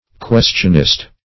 Search Result for " questionist" : The Collaborative International Dictionary of English v.0.48: Questionist \Ques"tion*ist\, n. 1.
questionist.mp3